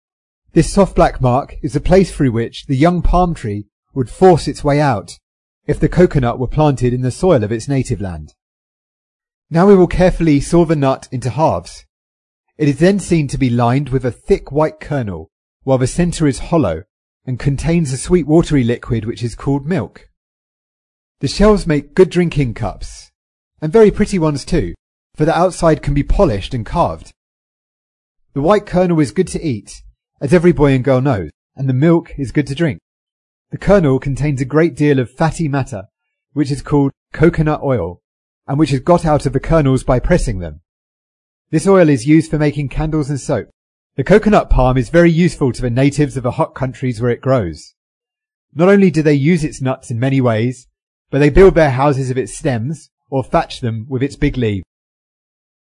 在线英语听力室提供配套英文朗读与双语字幕，帮助读者全面提升英语阅读水平。